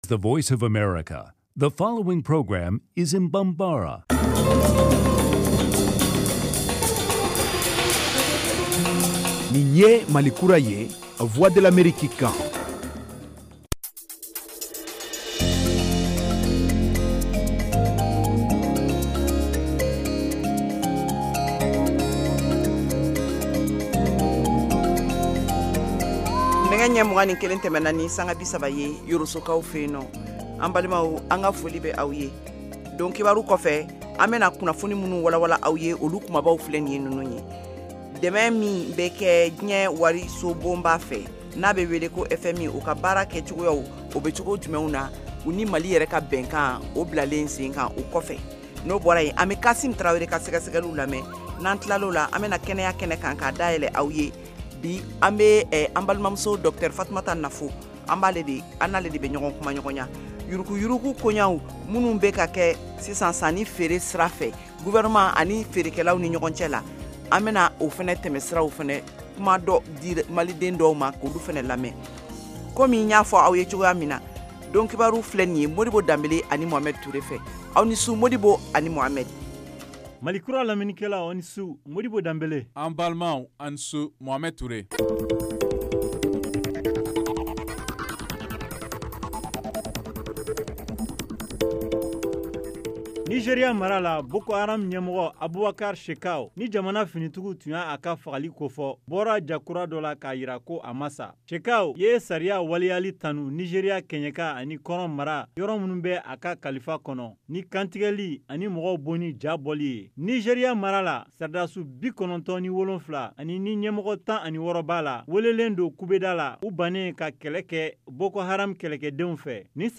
Emission quotidienne en langue bambara
en direct de Washington.